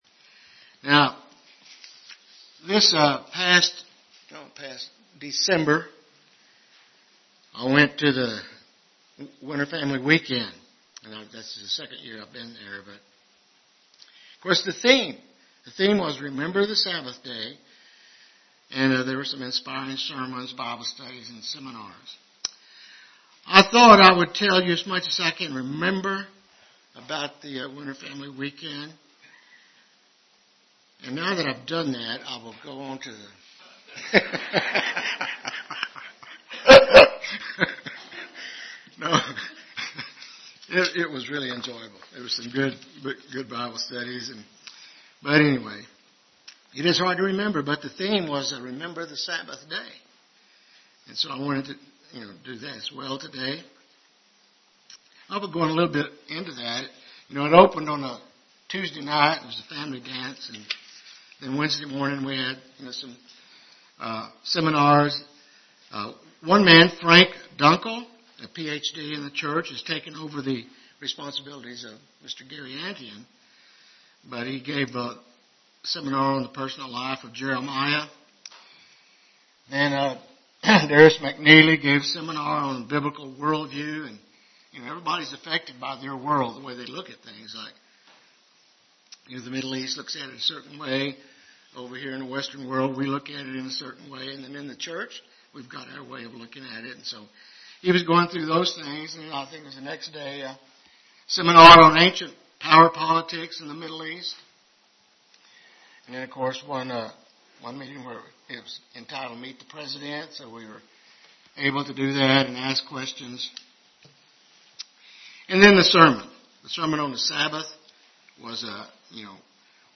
UCG Sermon Studying the bible?
Given in Lubbock, TX